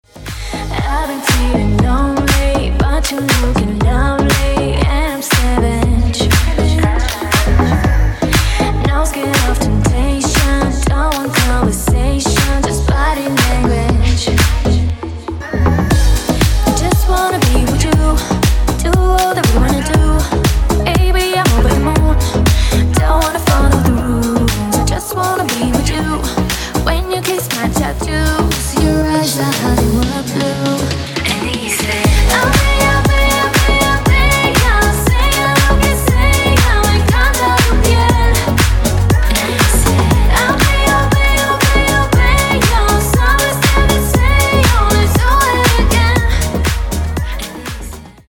женский вокал
deep house
dance
Electronic
EDM
vocal